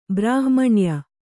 ♪ brāhmaṇua